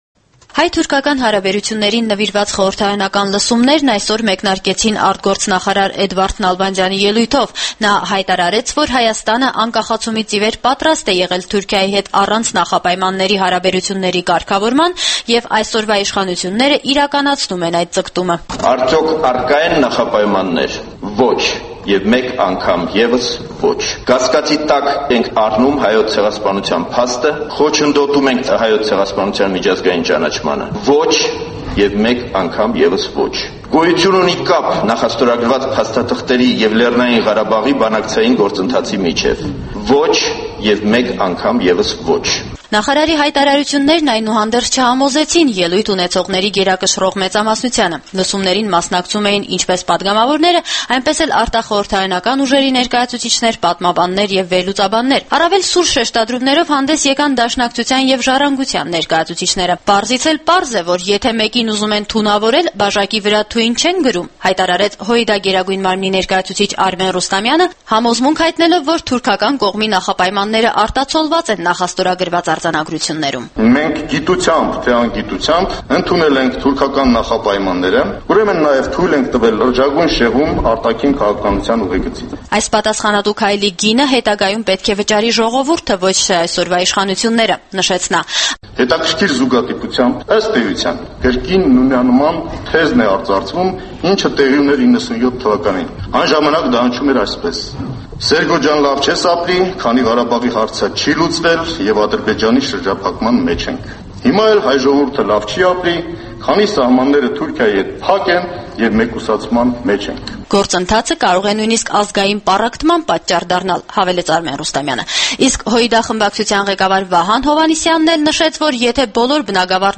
Էդվարդ Նալբանդյանը խորհրդարանում պարզաբանումներ է տալիս հայ-թուրքական կարգավորման վերաբերյալ